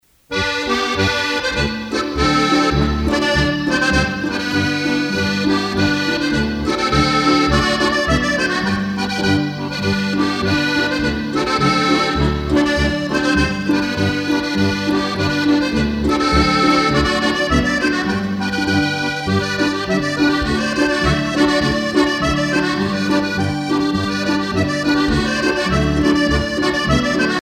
danse : polka
Pièce musicale éditée